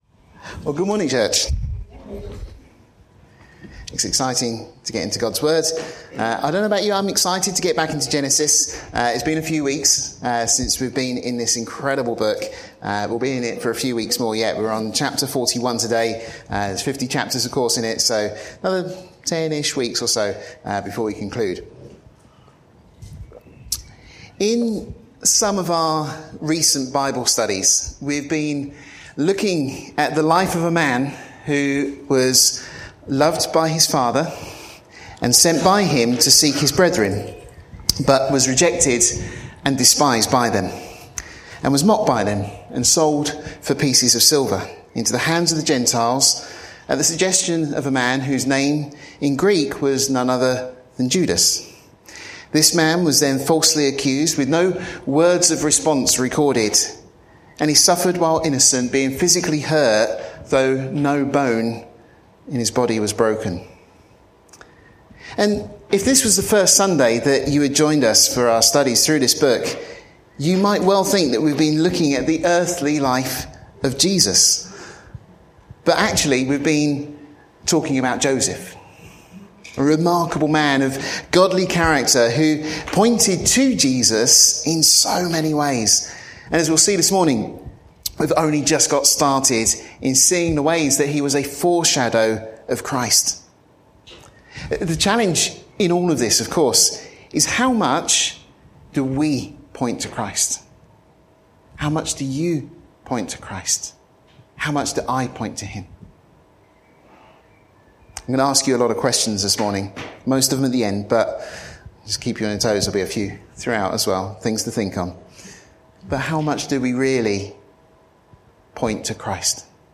This study is part of our series of verse by verse studies of Genesis, the 1st book in the Bible.